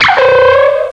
pokeemerald / sound / direct_sound_samples / cries / sawk.aif
-Replaced the Gen. 1 to 3 cries with BW2 rips.